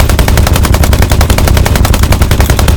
gun-turret-mid-3.ogg